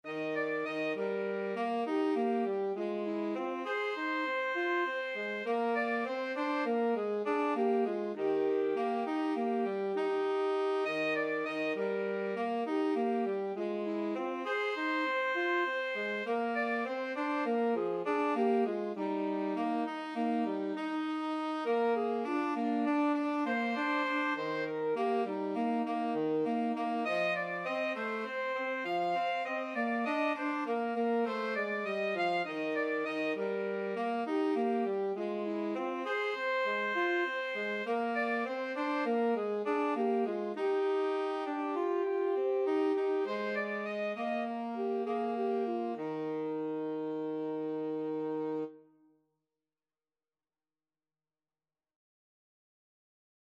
Alto SaxophoneTenor Saxophone
Moderato